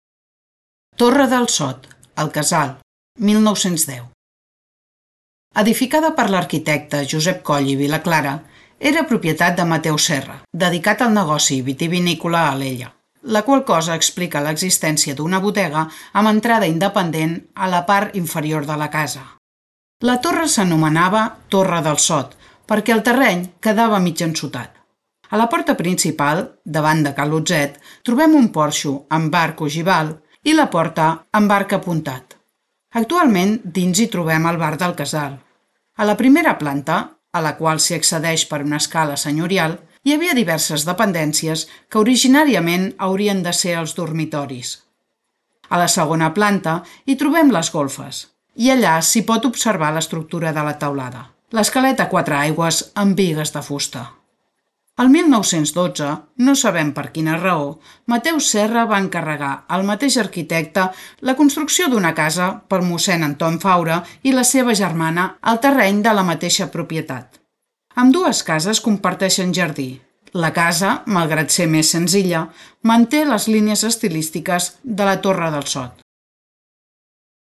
Ruta Modernista audioguiada